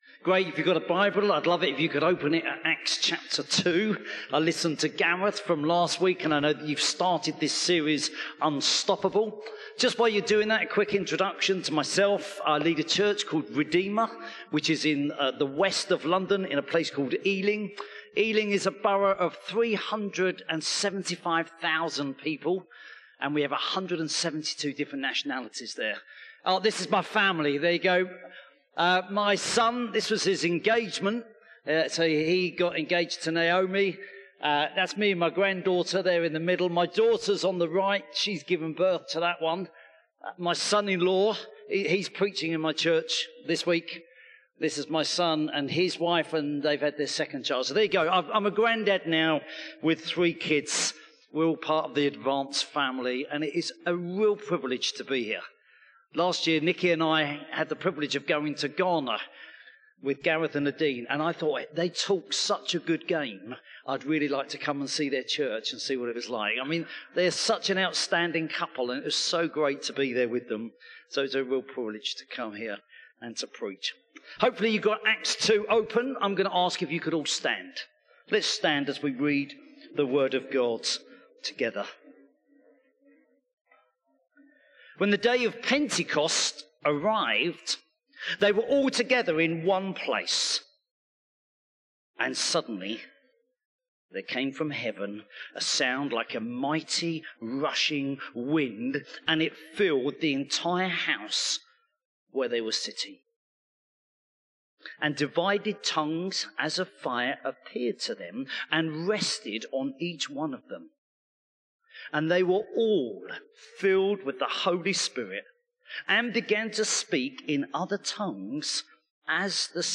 One-Hope-Sermon-8-March-2026.mp3